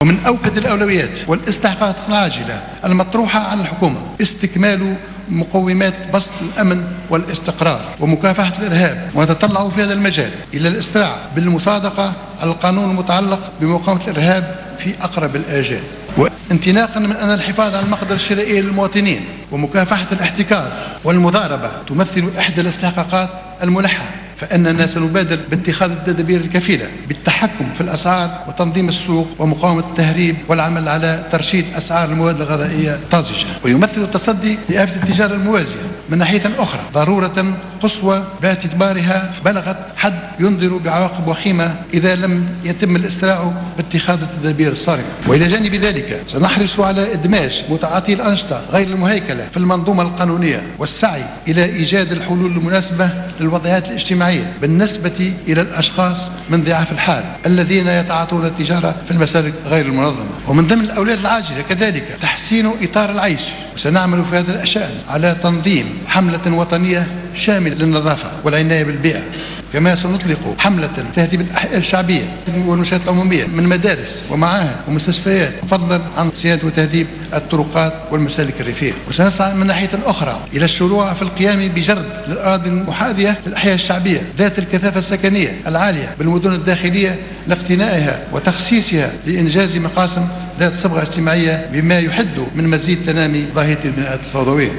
أكد رئيس الحكومة المكلف الحبيب الصيد اليوم الأربعاء خلال الجلسة العامة المخصصة للمصادقة على الحكومة بمجلس نواب الشعب أن من أوكد أولويات حكومته هو استكمال مقومات بسط الأمن ومقاومة الإرهاب مشيرا إلى أن انه يتطلع الى الإسراع في المصادقة على القانون المتعلق بمقاومة الإرهاب في أقرب الاجال على حد قوله.